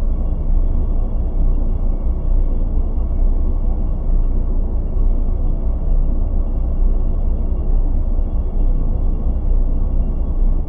viraldrone.wav